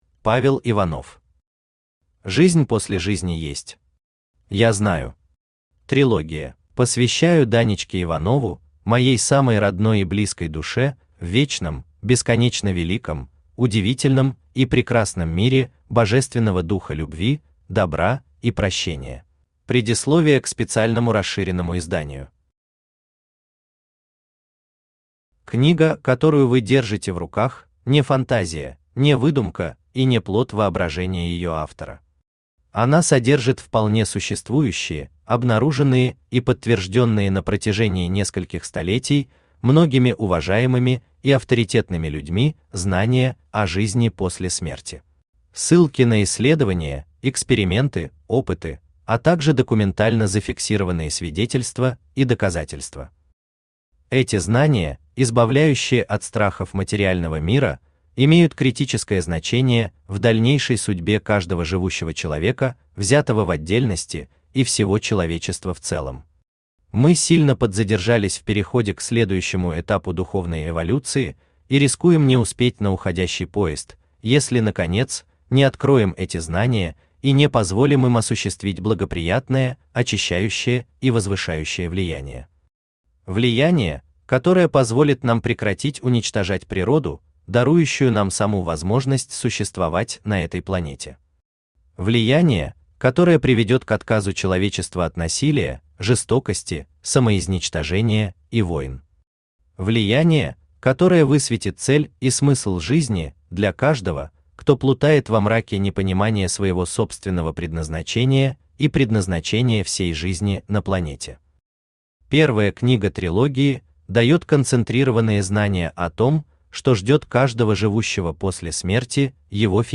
Трилогия Автор Павел Иванов Читает аудиокнигу Авточтец ЛитРес.